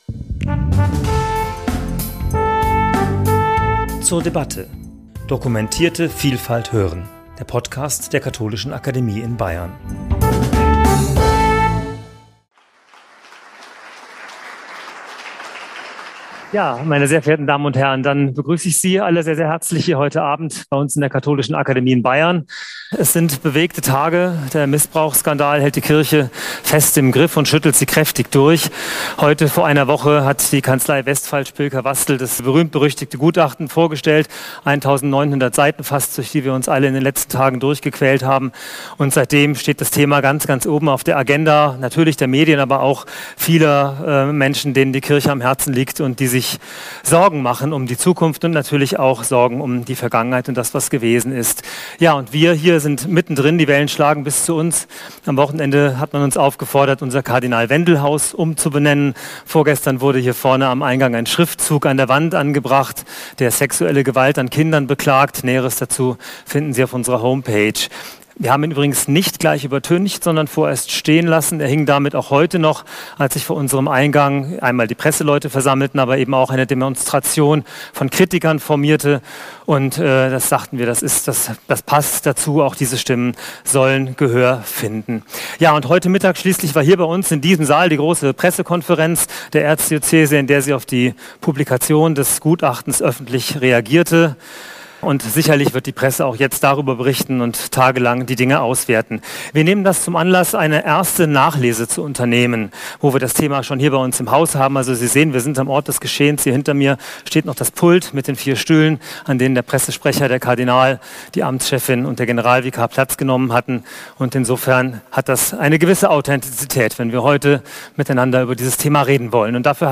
Podium zum Thema 'Differenzierungen zum Münchner Missbrauchsgutachten' ~ zur debatte Podcast